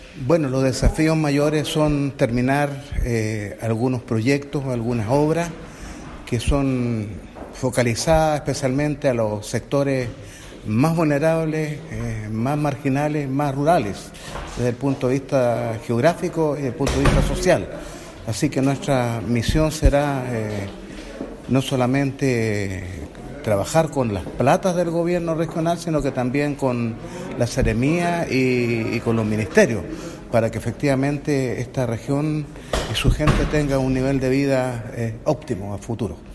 En la comisión de Desarrollo Social, se mantendría presidiendo el consejero regional Lombardo Toledo, quien se refirió a los principales desafíos señalando que